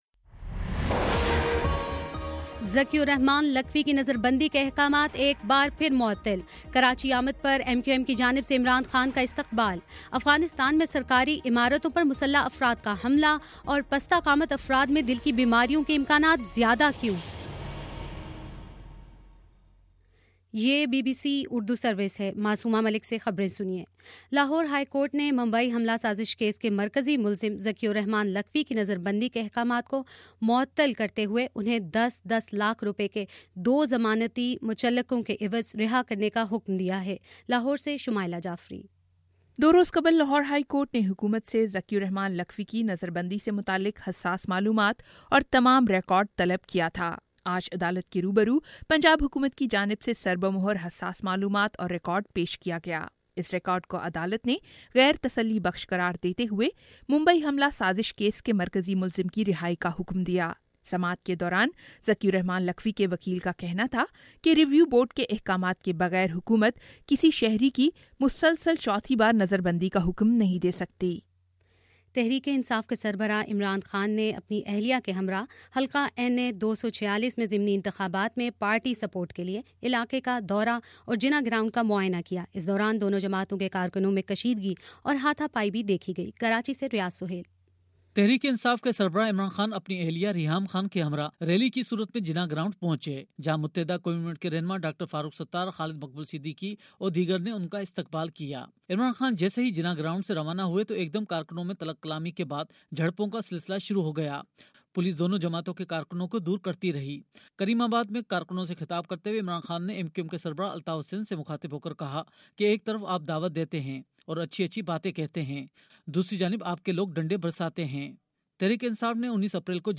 اپریل 9: شام پانچ بجے کا نیوز بُلیٹن